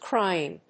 /kɹaɪ̯.ɪŋ(米国英語)/